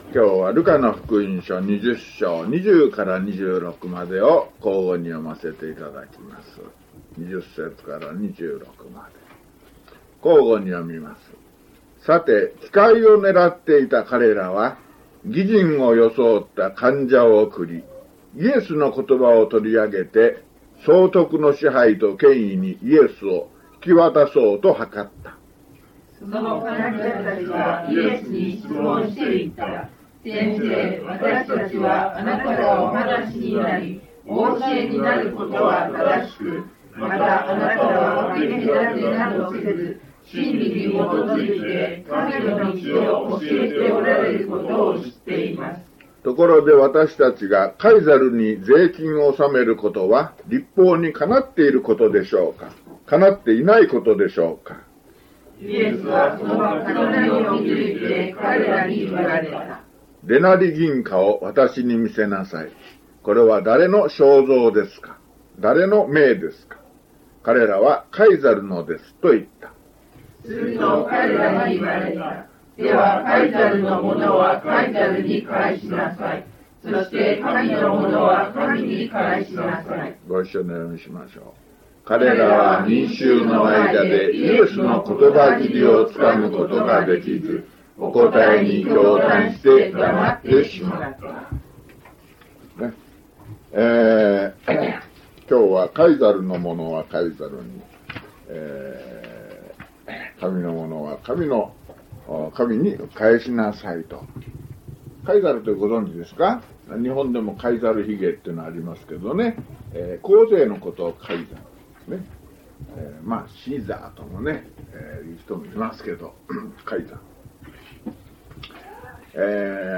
luke155mono.mp3